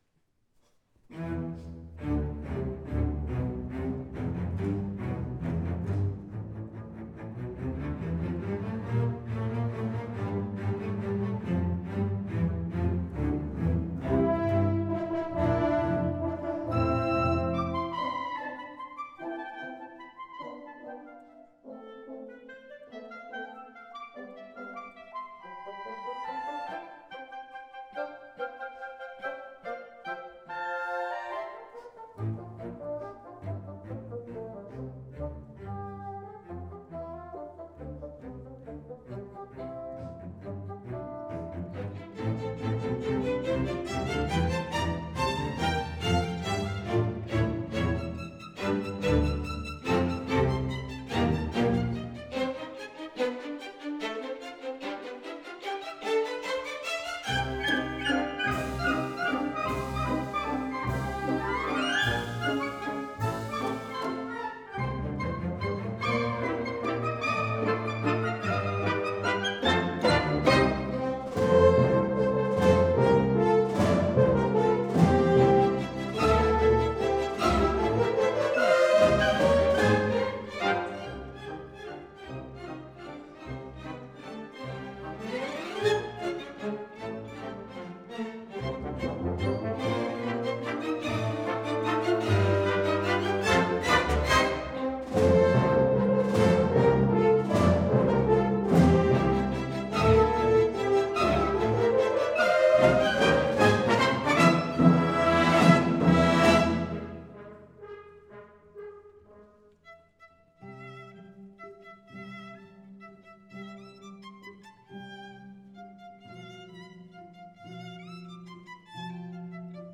Live à la salle de Chisaz à Crisser, 13 Octobre 2023